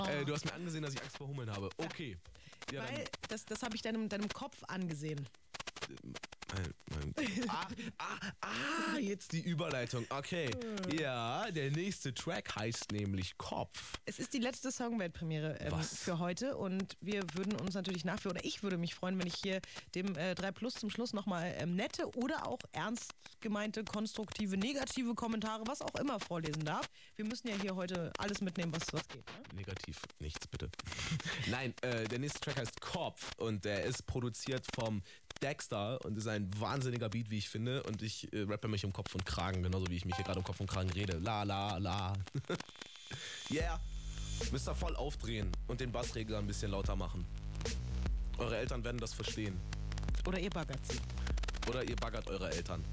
2. Schlechter Empfang mit dem FM Radio
Beim FM Radio entstehen Störgeräusche bei jedem Sender, auf freiem Feld mit verschiedenen Kopfhörern.